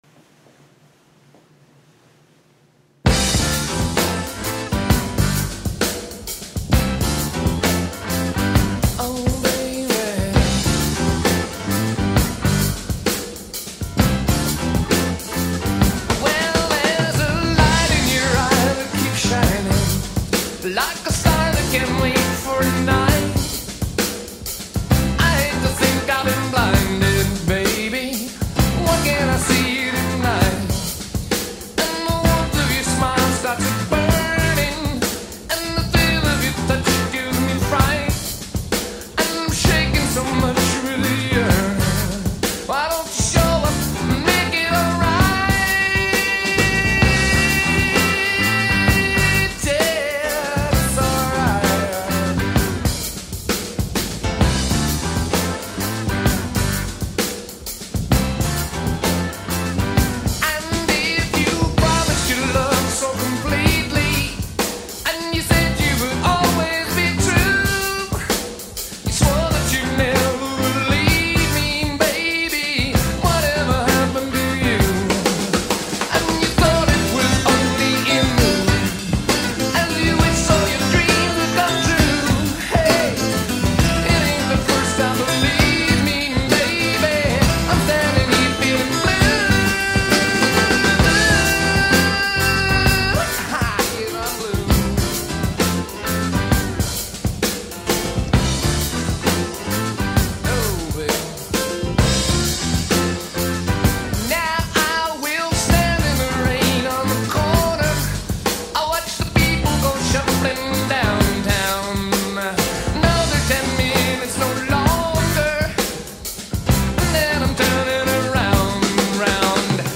El bajo toca el piano y la guitarra es de doce cuerdas.